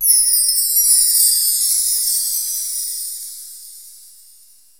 MD (FX Bells).wav